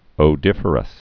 (ō-dĭfə-rəs)